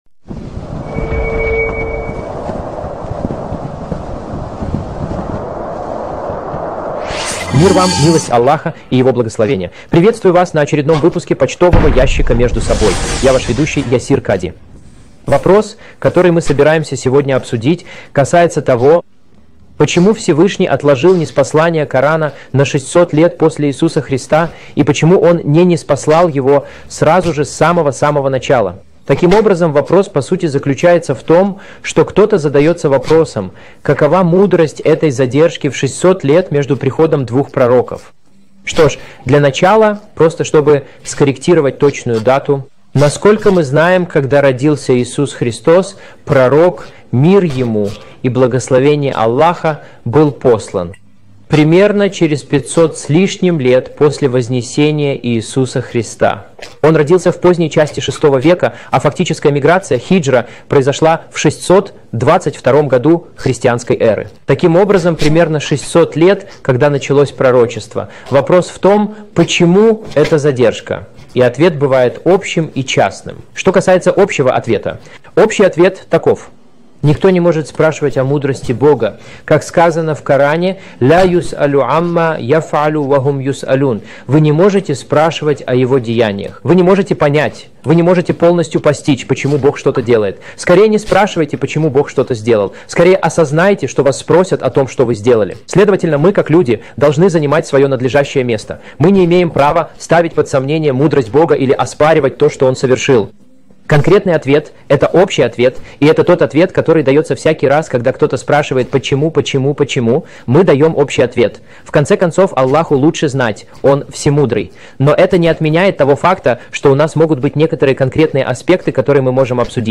Описание: В этом выпуске «Mailbag» Ясир Кади отвечает на вопрос: «Почему Бог ждал 600 лет после Иисуса, чтобы ниспослать Священный Коран?»